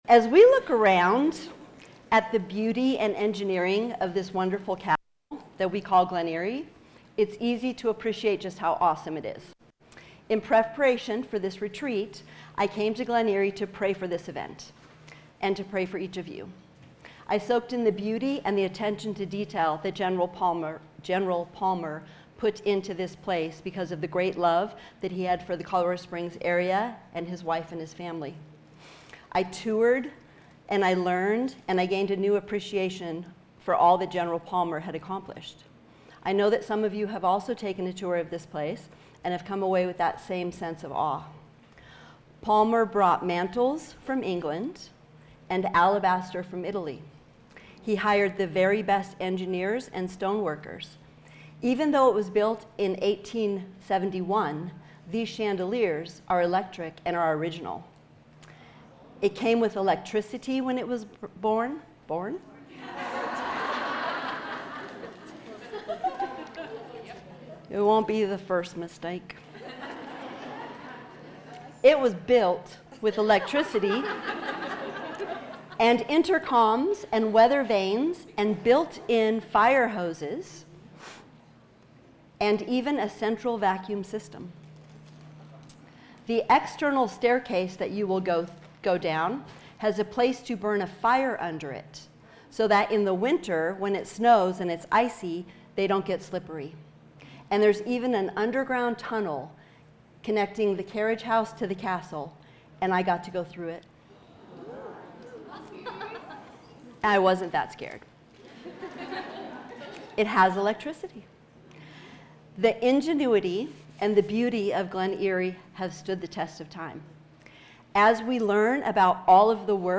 Conference and retreat teachings.